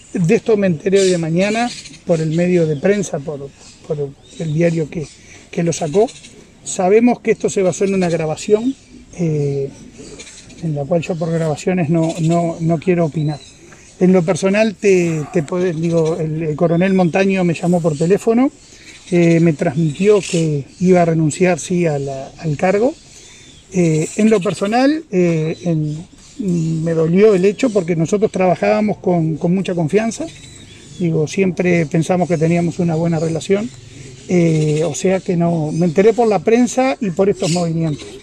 “En lo personal me dolió” expresó el presidente de ASSE.